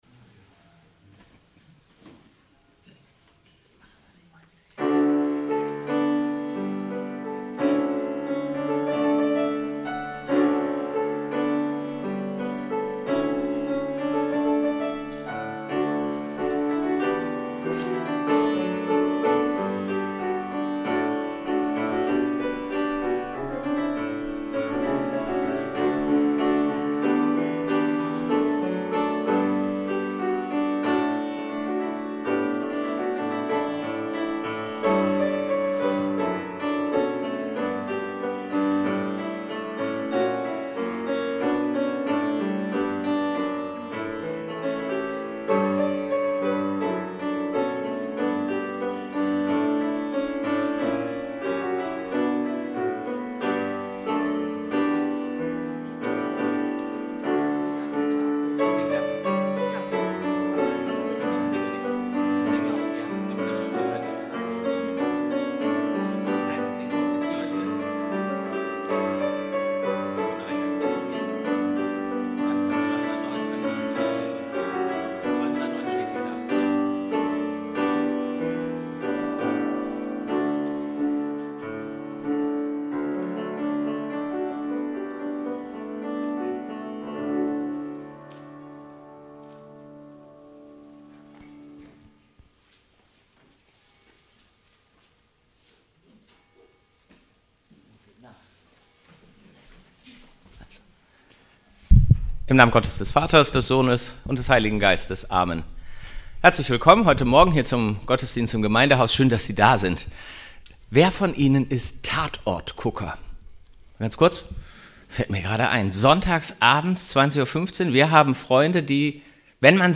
Gottesdienst - 18.01.2026 ~ Peter und Paul Gottesdienst-Podcast Podcast